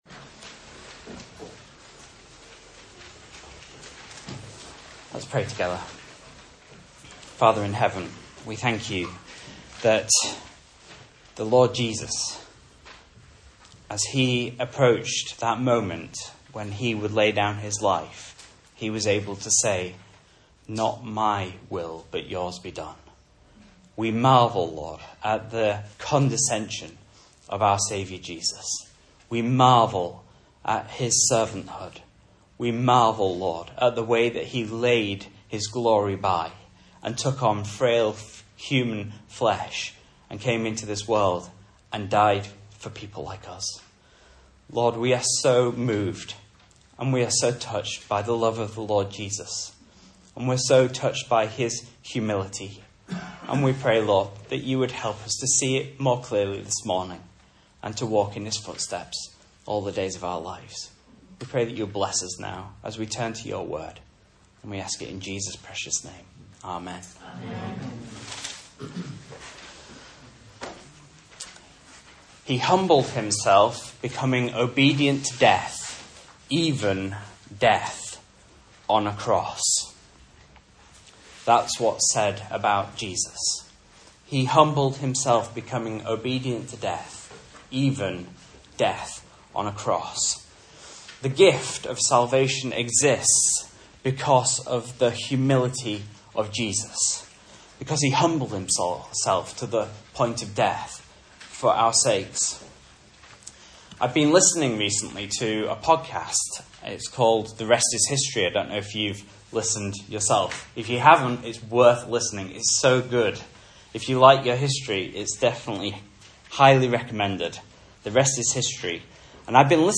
Message Scripture: Mark 9:33-50 | Listen